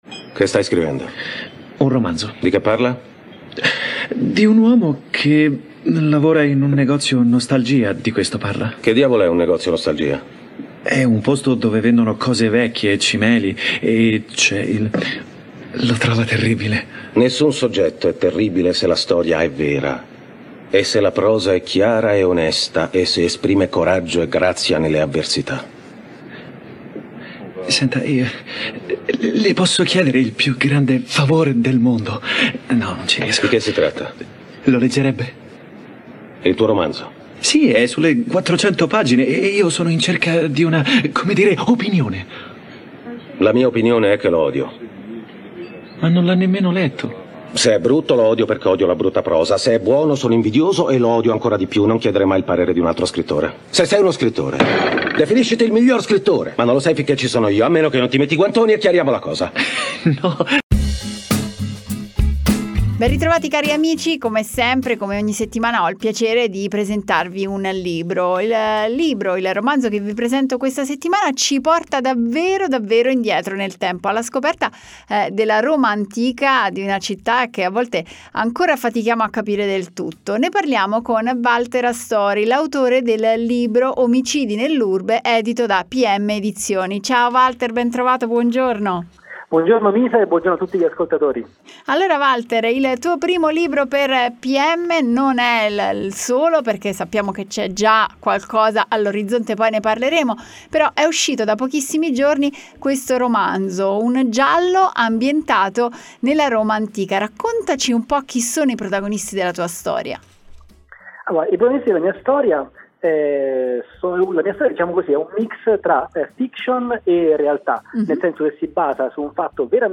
Nel corso della trasmissione radiofonica in onda su Radio Cusano Campus